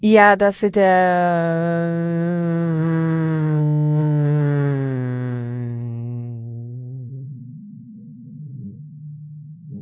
Worse, the model sometimes gets stuck in repetition loops like:
Ja das it ähhhhhhhhhhhhhhhhhhhhhhhhhh
random_speaker_base_model_hangup.wav